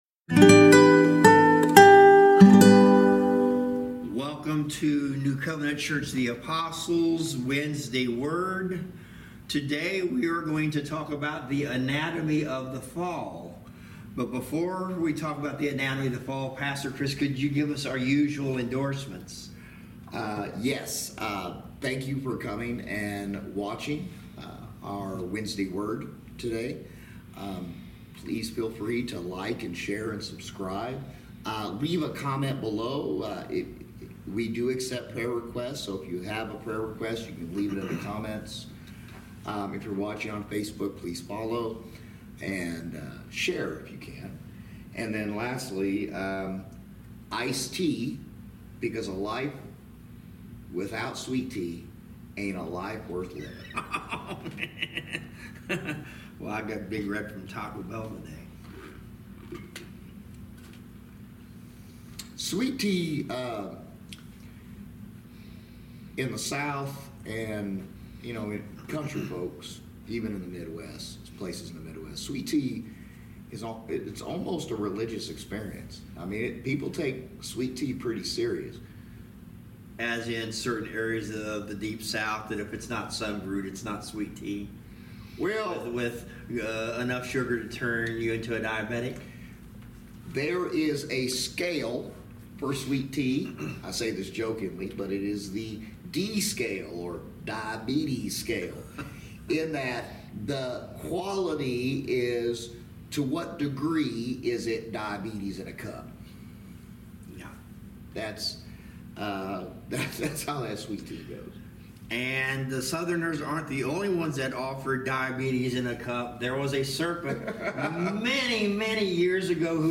Passage: Romans 5:12-19 Service Type: Wednesday Word Bible Study